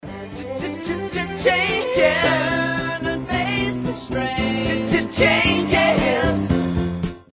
(Here’s a brief musical interlude to enhance that last point.)